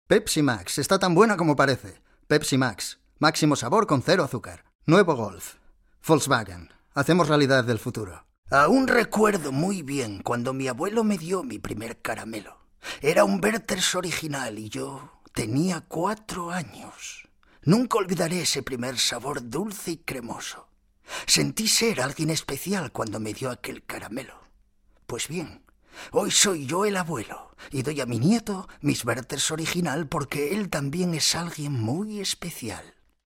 Male
Adult (30-50)
Warm and confident, a voice for all seasons.
Radio Commercials
All our voice actors have professional broadcast quality recording studios.
0117Salesy__commercial_demo_Mp3.mp3